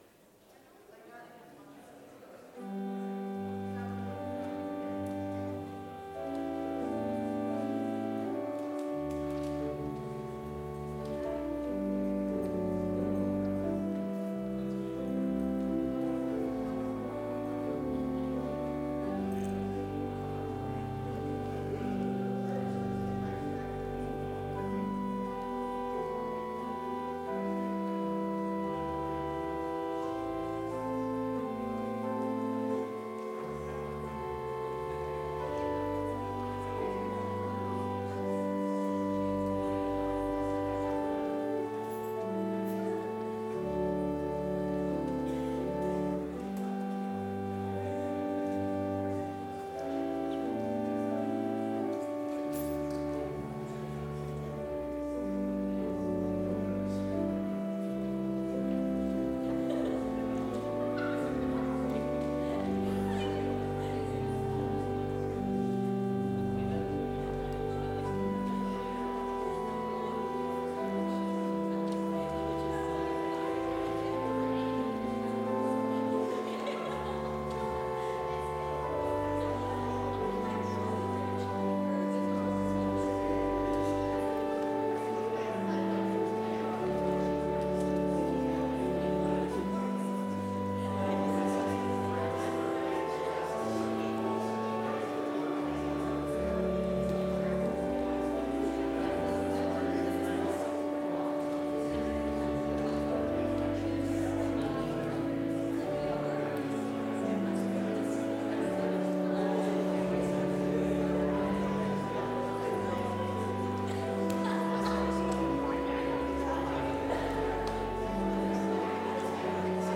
Complete service audio for Chapel - March 23, 2023
Order of Service Prelude Hymn 432 - Jesus, Thy Blood and Righteousness